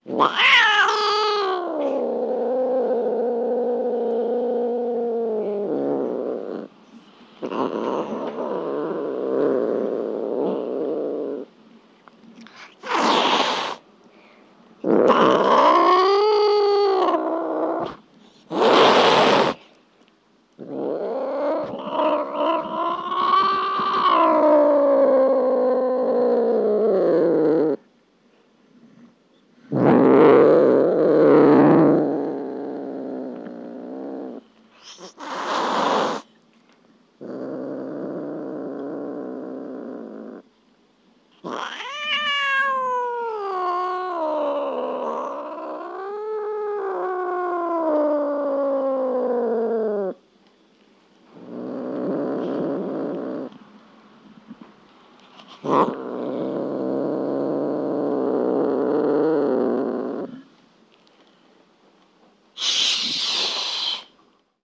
Звуки кошек